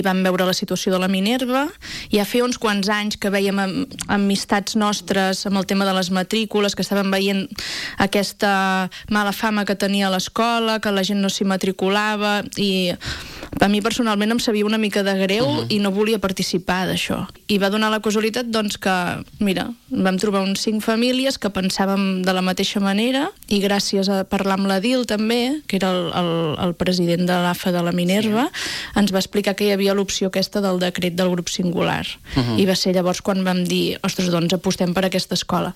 han passat aquest dilluns pel matinal de RCT per compartir la seva experiència després de dos mesos de curs.